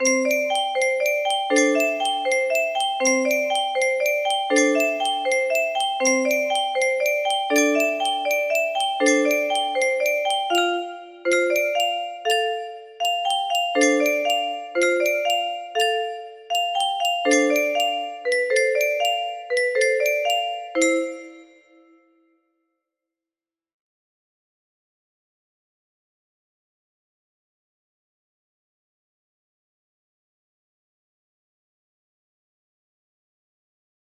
sparkle music box melody
Grand Illusions 30 (F scale)